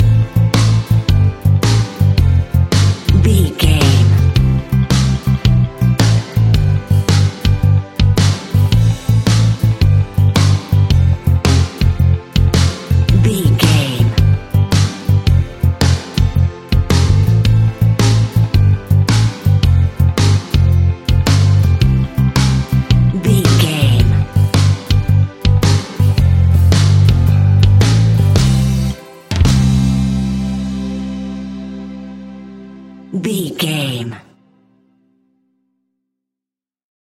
Ionian/Major
melancholic
energetic
smooth
uplifting
electric guitar
bass guitar
drums
pop rock
organ